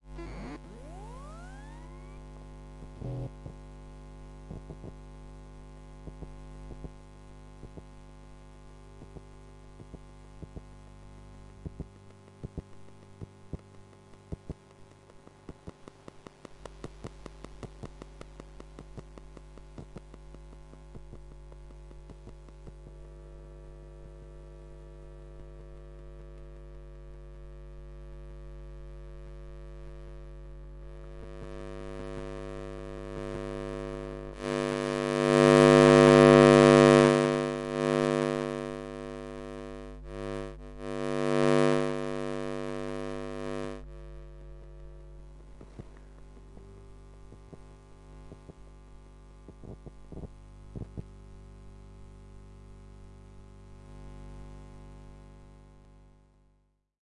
电磁场 " 电视电缆盒
描述：使用Zoom H1和电磁拾音器录制